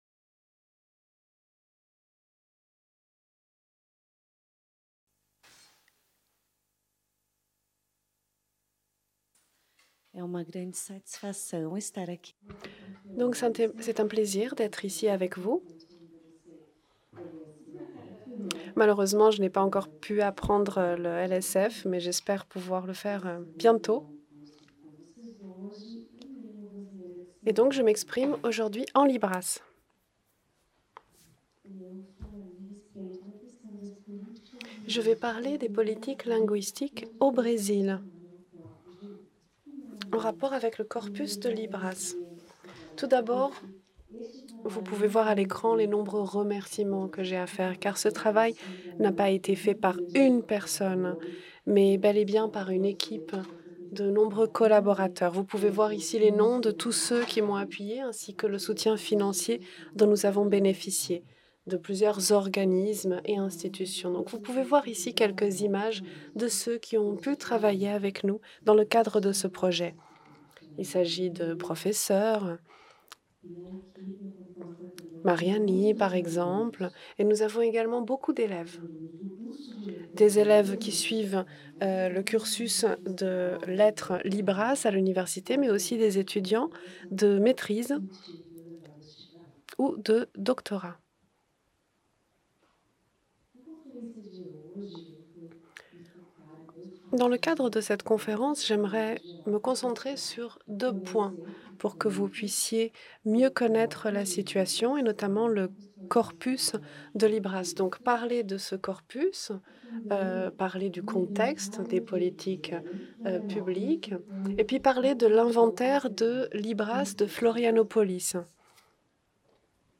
Conférence plénière) : Corpus de Libras: políticas linguísticas e a Libras no Brasil